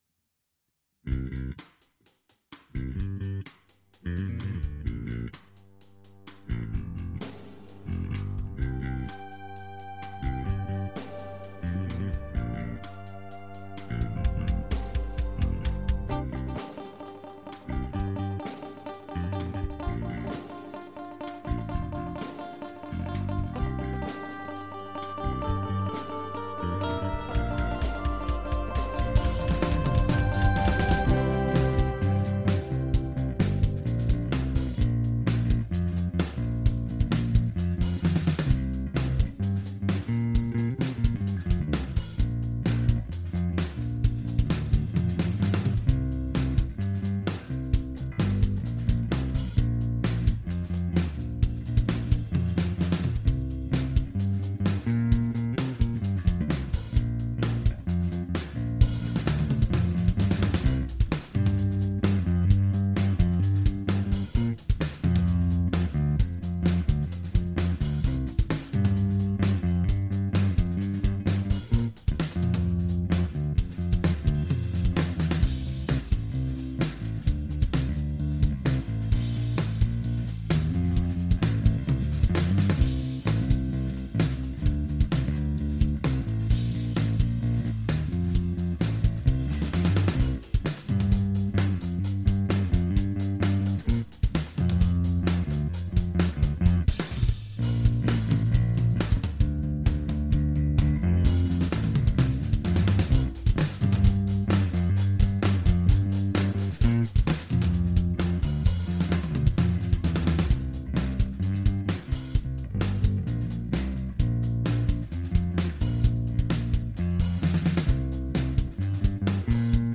Strangeways - intro drums and bass - unmixed